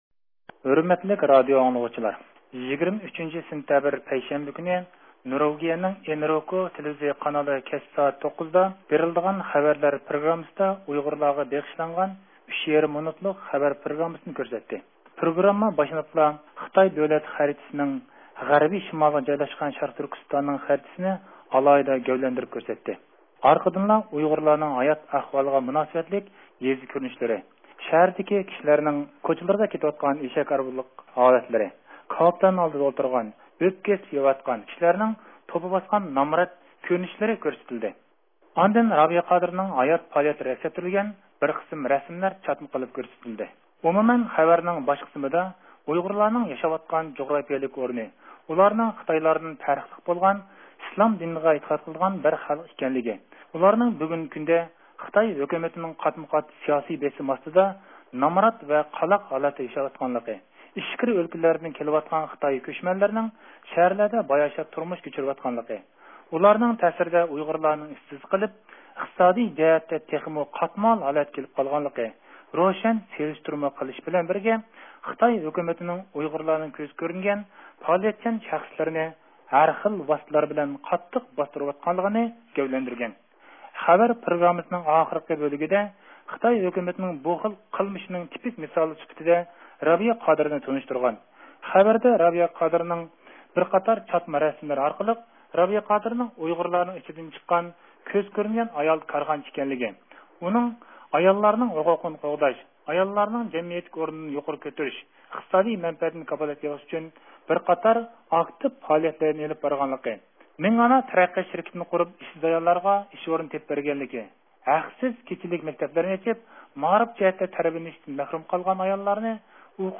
23 – سېنتەبىر نورۋىگىيىدىكى بىر تېلېۋىزىيە قانىلىدا ئۇيغۇرلارنىڭ ھازىرقى ئەھۋالى ۋە خىتاي تۈرمىسىدە جاپا چېكىۋاتقان رابىيە قادىر خانىمنى تونۇشتۇرغان ئۈچ يېرىم مىنۇتلۇق بىر پروگرام كۆرسىتىلدى. ئۇنىڭدا ئاتاقلىق ئۇيغۇر كارخانىچى، ئۇيغۇر خەلقىنىڭ پەخىرلىك قىزى رابىيە قادىر خانىمنىڭ خىتاي دائىرلىرى تەرىپىدىن ئاساسسىز قولغا ئېلىنىپ، تۈرمىگە تاشلانغانلىقى ھەققىدە ئۇچۇرلار بېرىلدى